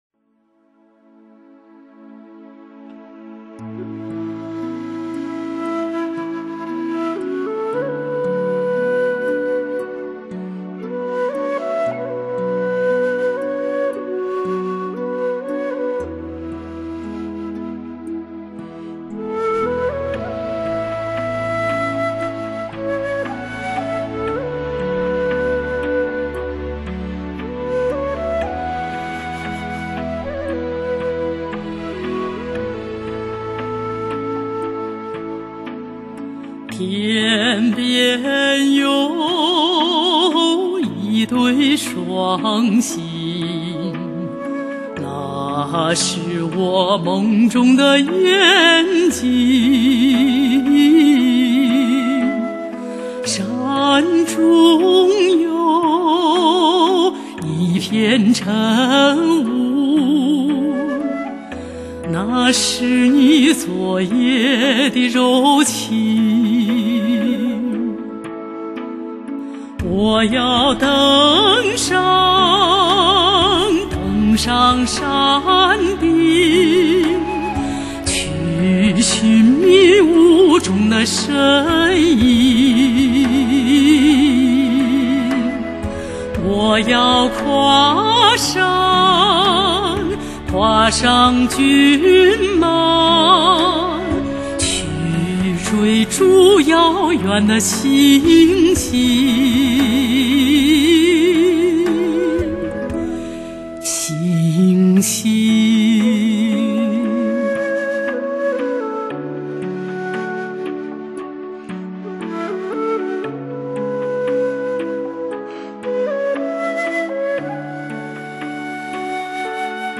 享誉世界的女中音歌唱家， 首度跨界演绎充满浓郁民族特色的蒙藏歌曲。